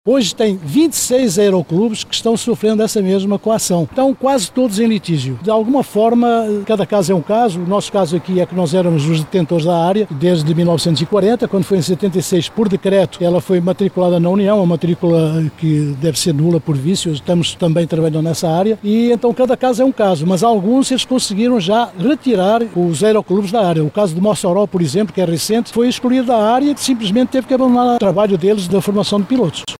Em uma entrevista coletiva